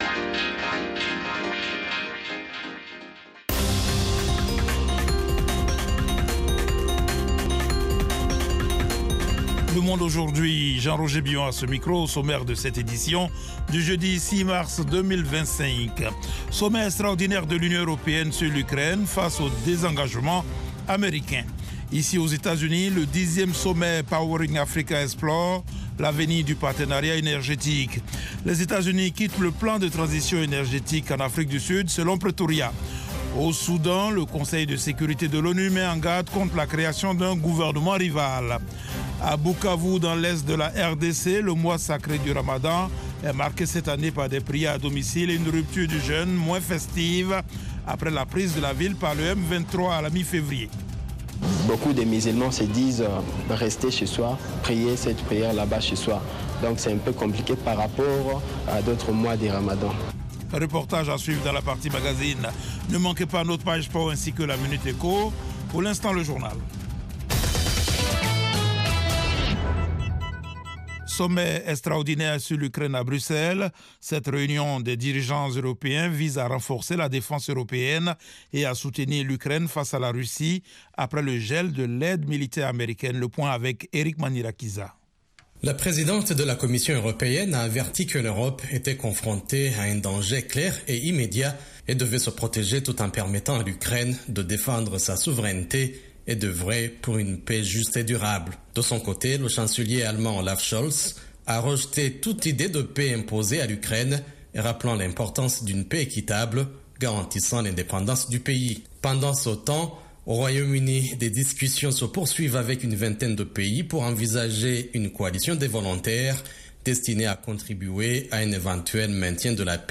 Le programme phare du service francophone dure 30 minutes sur les informations de dernières minutes, des reportages de nos correspondants, des interviews et analyses sur la politique, l’économie, les phénomènes de société et sur la société civile.